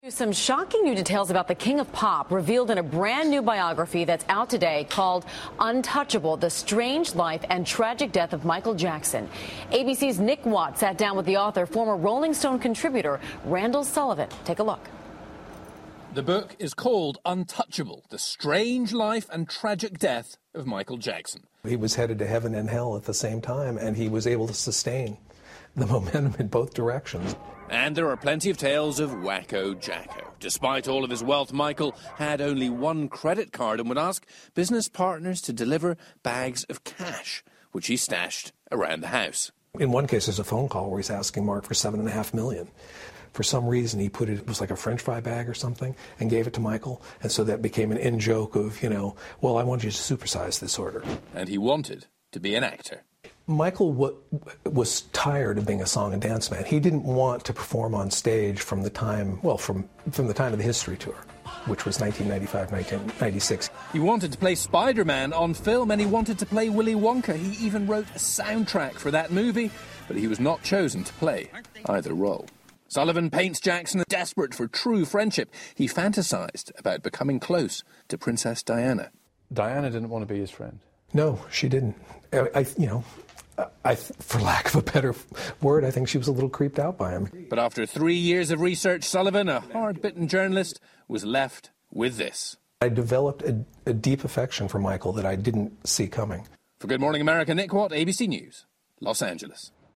访谈录 2012-11-21&11-23 追忆迈克尔.杰克逊 听力文件下载—在线英语听力室